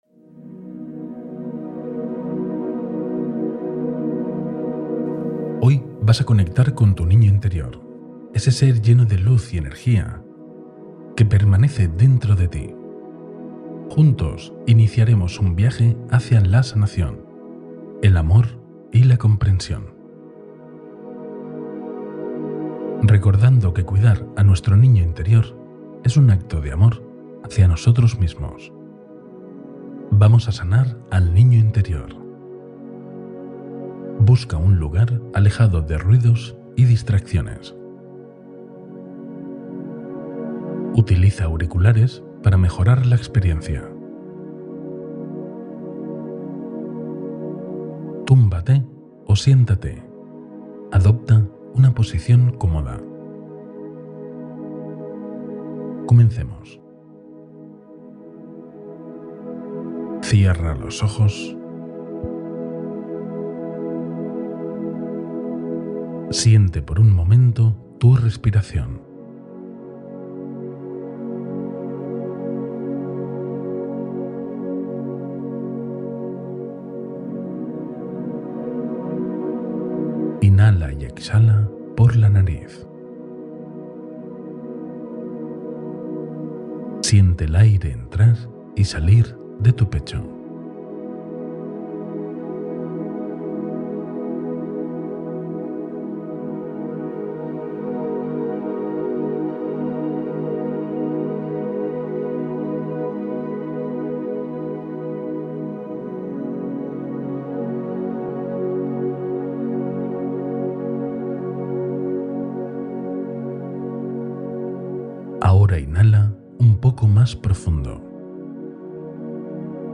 Sana a tu niña o niño interior con esta meditación transformadora